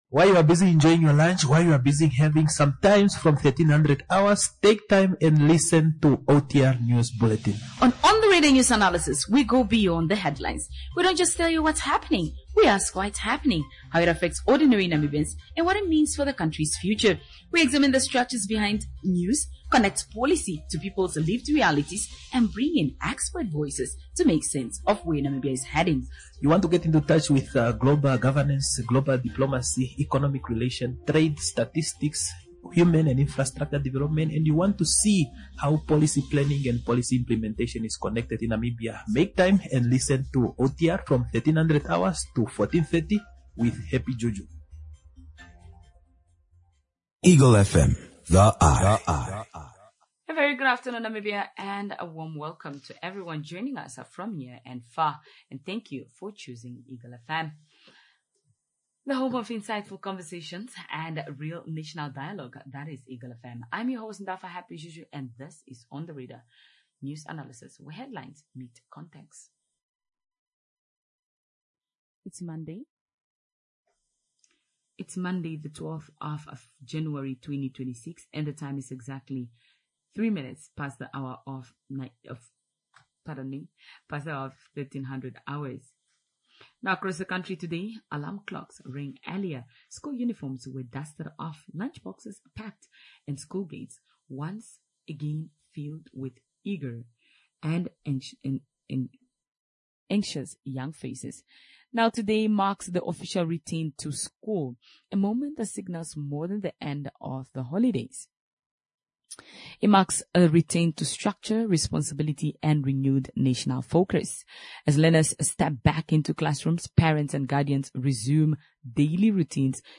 2. School has officially resumed today, conversation alongside Oshana Governor Hofni Iipinge.
A discussion on GIPF Pension Backed Home Loan Scheme set to commence commence